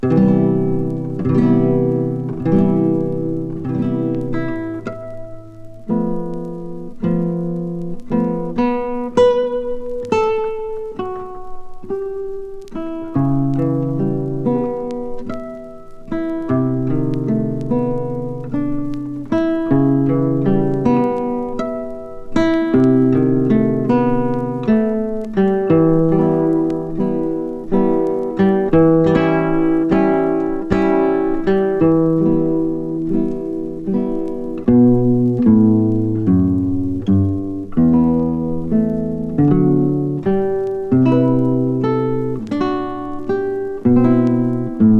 ギター、ベース、ドラム、フルートやテナーサックスによる演奏。
Jazz　USA　12inchレコード　33rpm　Mono